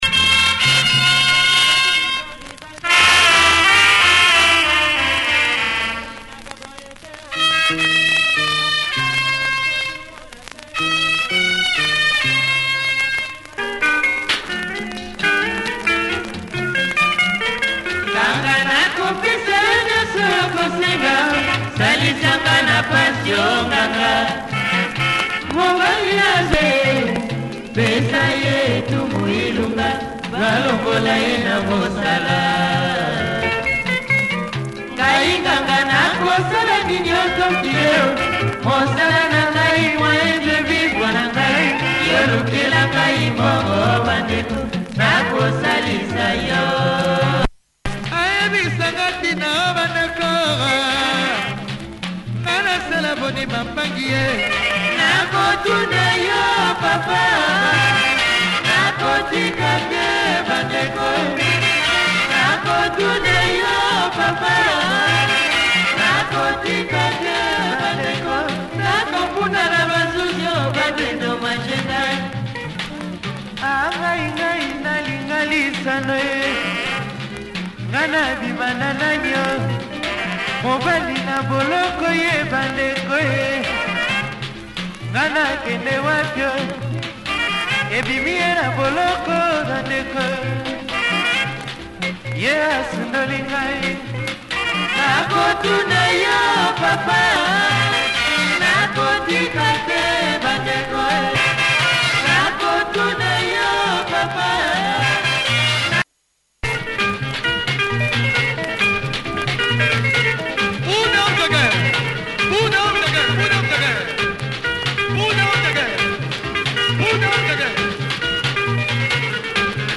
Slightly ridden copy but plays okay as you can hear!